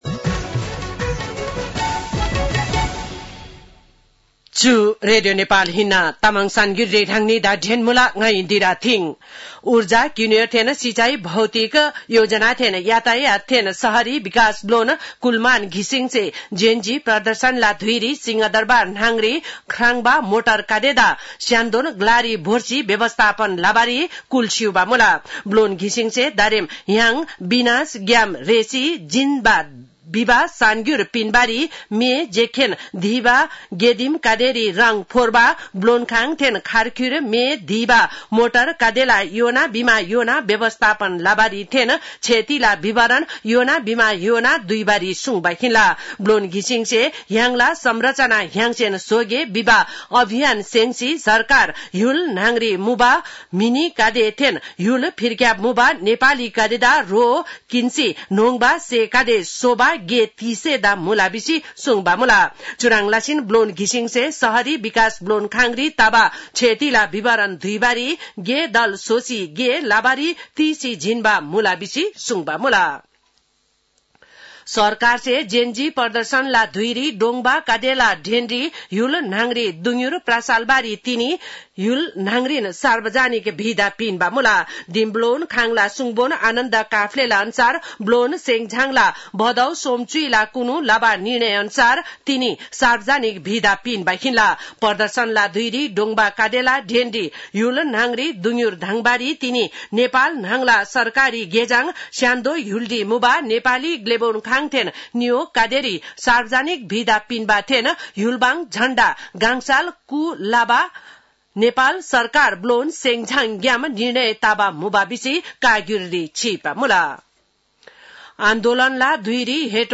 तामाङ भाषाको समाचार : १ असोज , २०८२
Tamang-news-6-01.mp3